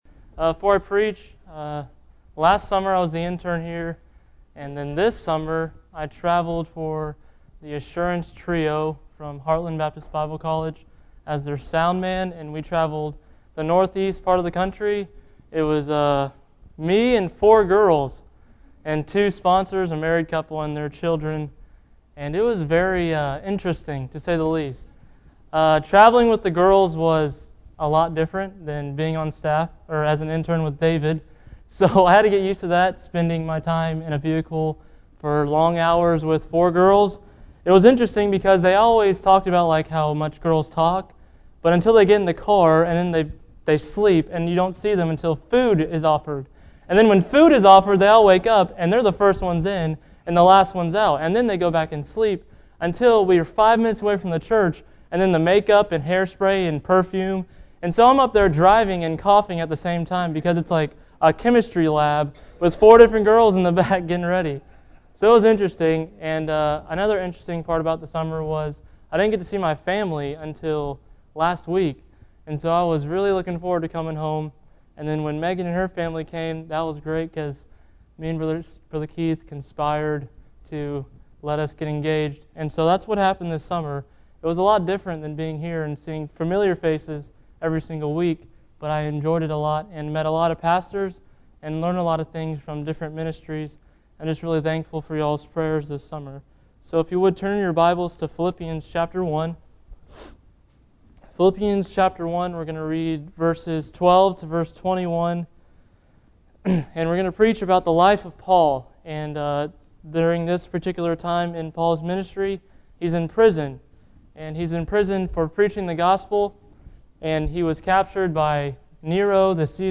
Bible Text: Philippians 1 | Preacher